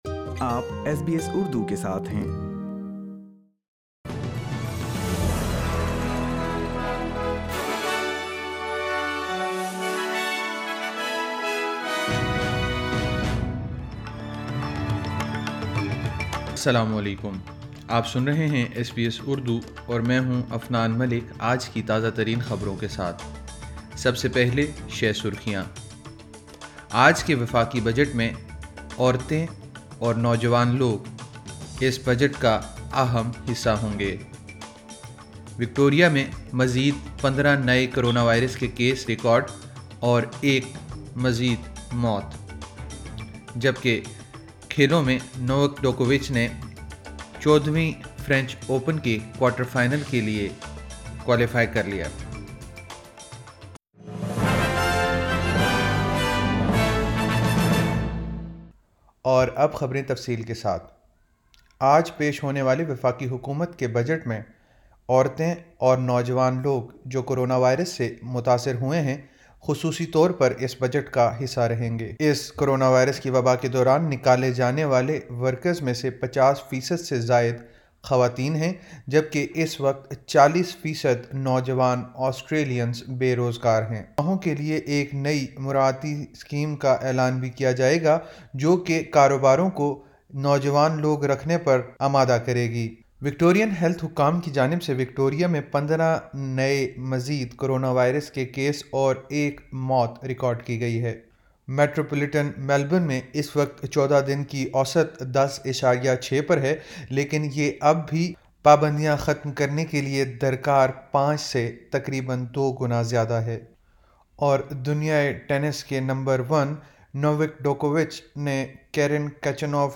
ایس بی ایس اردو خبریں 06 اکتوبر 2020